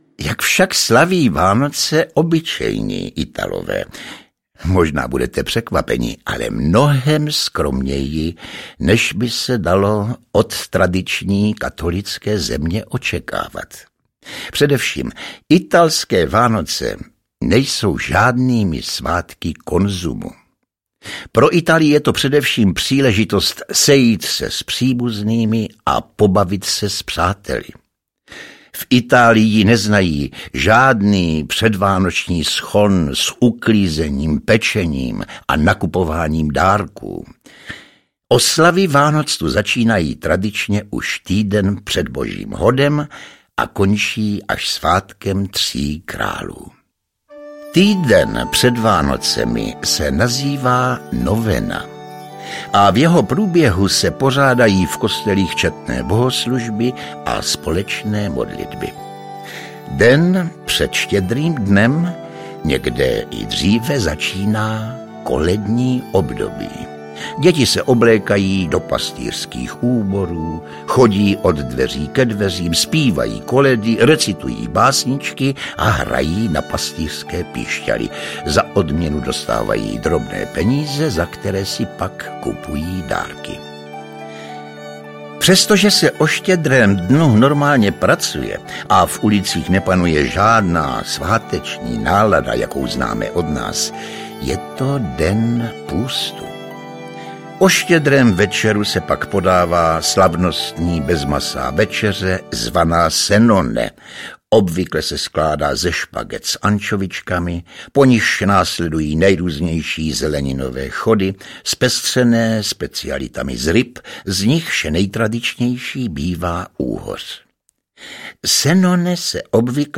Ukázka z knihy
Herec Josef Somr vypráví dětem i dospělým o vánočních tradicích a zvycích v různých koutech světa. Pásmo vánočních příběhů a vánočních písní z 16 zemí. Jedinečnou atmosféru nahrávky podtrhuje špičkové hudební provedení jednotlivých koled a melodií.
Vánoční vyprávění s hudbou nás zavede na vánoční oslavy do Betléma, Austrálie, Číny i Afriky.